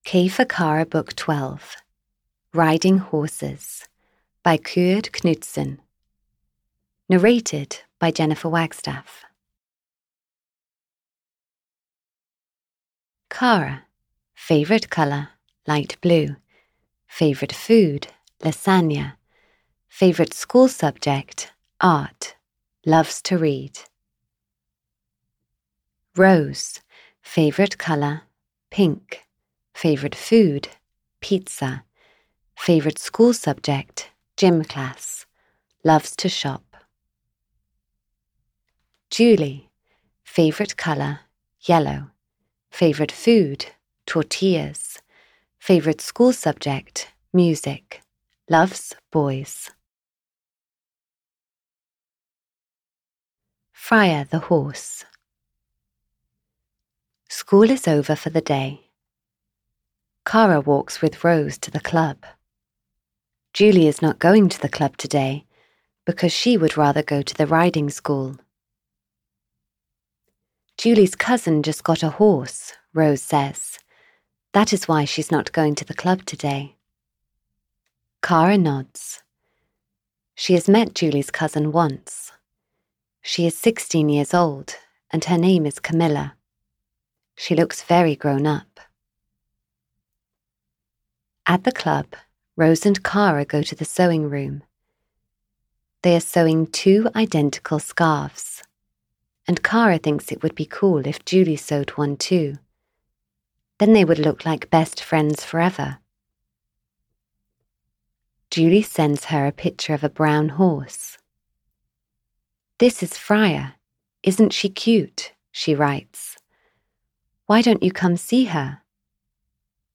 Audio knihaK for Kara 12 - Riding Horses (EN)
Ukázka z knihy